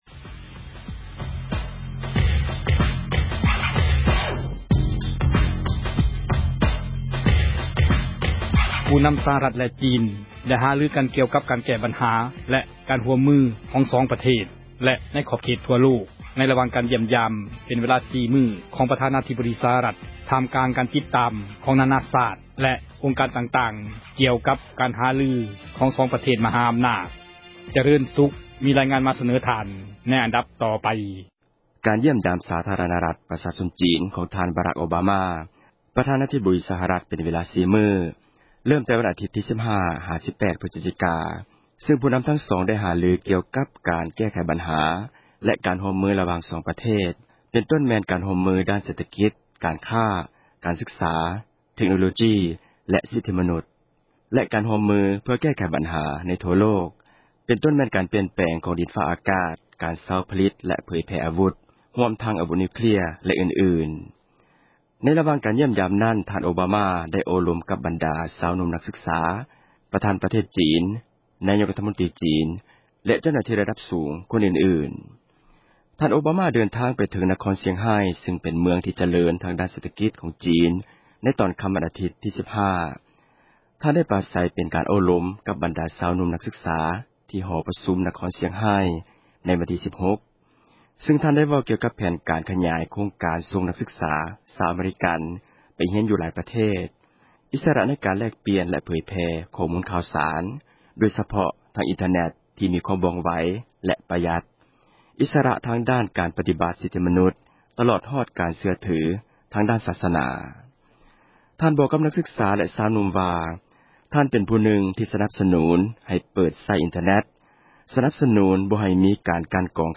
ມີຣາຍງານ ມາສເນີທ່ານ ໃນອັນດັບຕໍ່ໄປ…